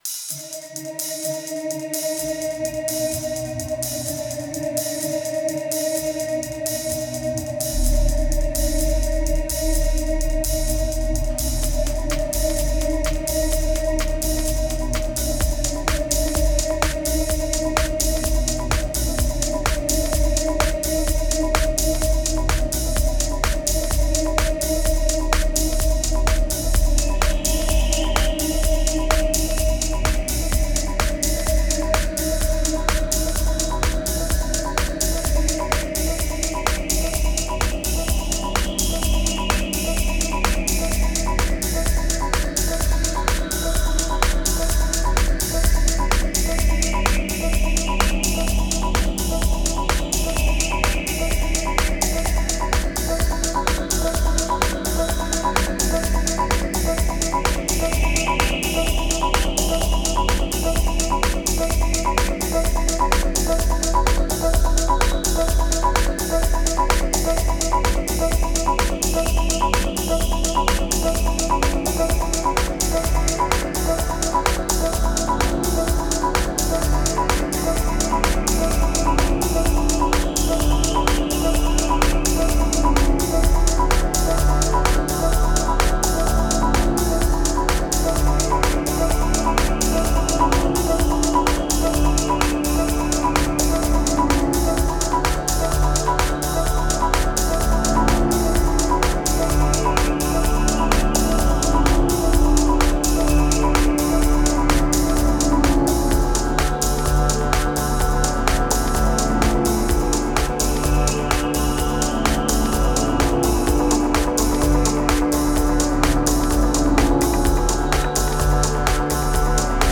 1574📈 - 66%🤔 - 127BPM🔊 - 2024-05-20📅 - 1061🌟
Feels like sunday with a twist.